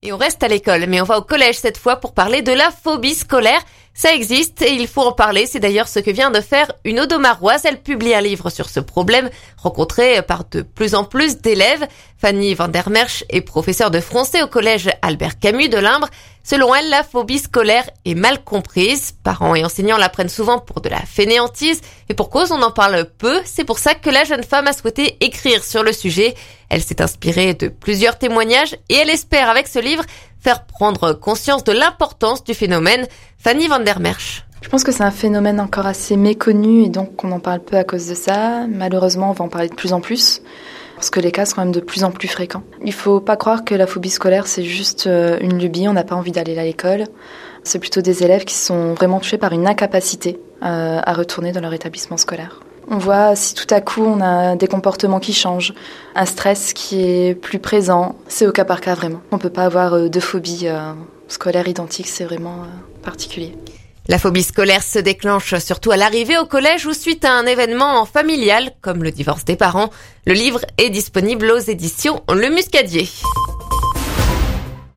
Extrait : «C’est un phénomène encore assez méconnu, donc on en parle peu à cause de cela. Malheureusement, on va en parler de plus en plus, parce que les cas sont de plus en plus fréquents.»